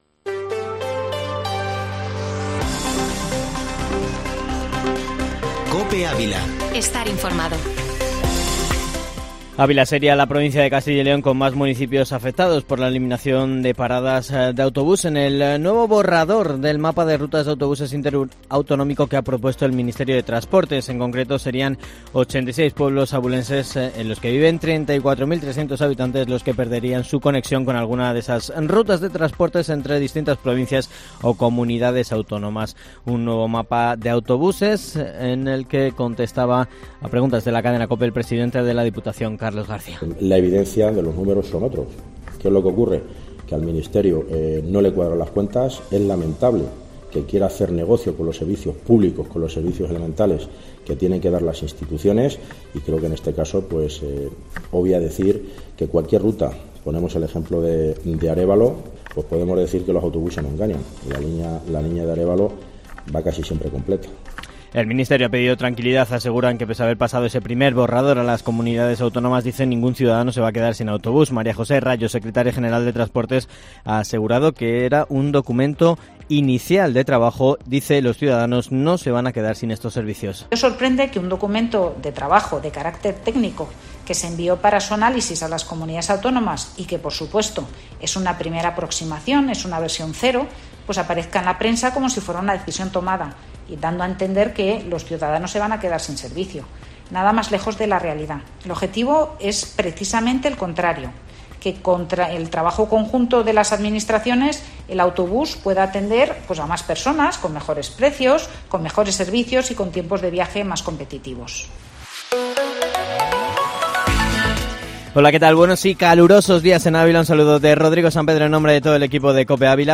Informativo Matinal Herrera en COPE Ávila 13-julio